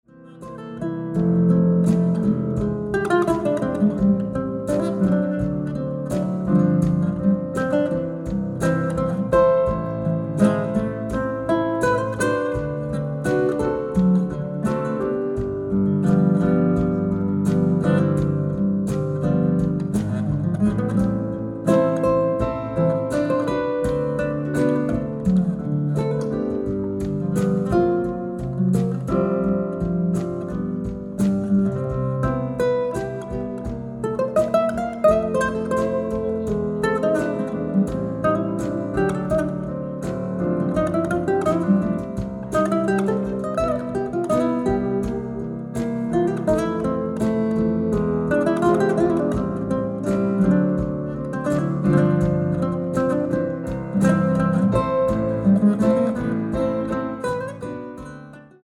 ライブ・アット・ケズィック・シアター、フィラデルフィア 10/23/2011
※試聴用に実際より音質を落としています。